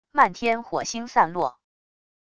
漫天火星散落wav音频